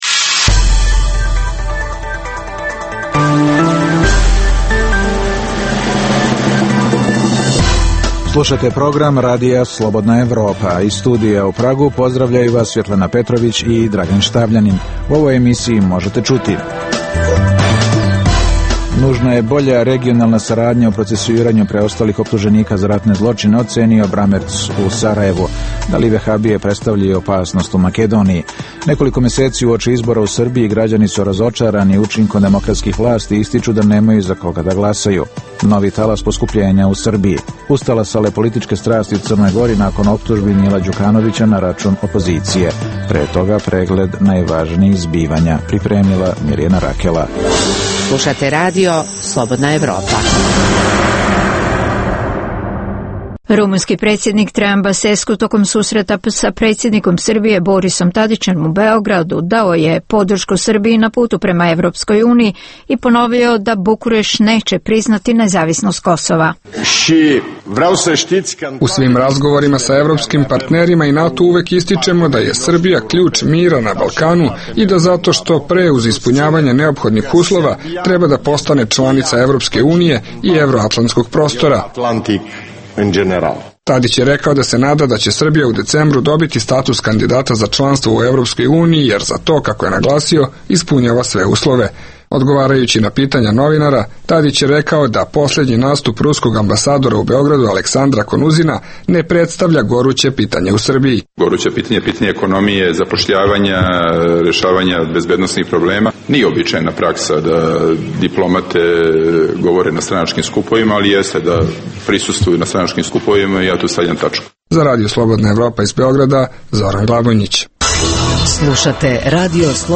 - Reportaža sa barikada na severu Kosova.